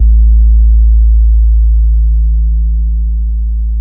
XP SUBASE E1.wav